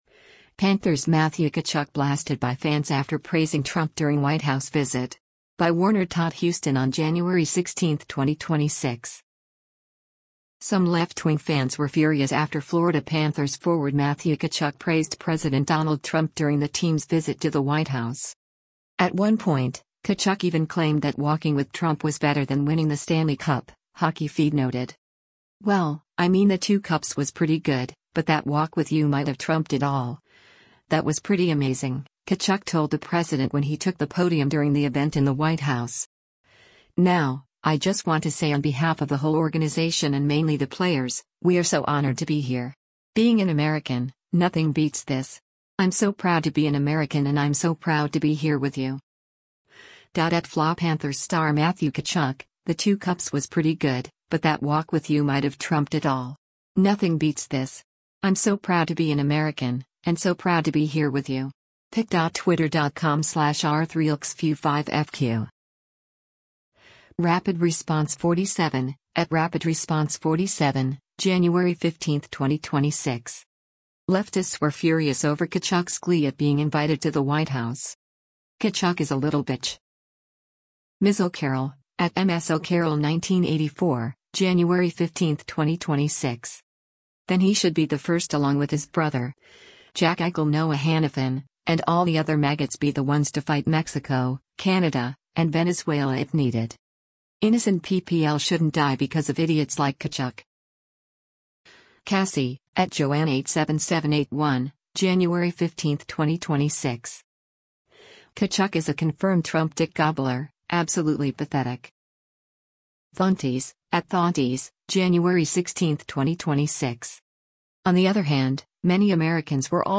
“Well, I mean the two cups was pretty good, but that walk with you might’ve trumped it all, that was pretty amazing,” Tkachuk told the president when he took the podium during the event in the White House.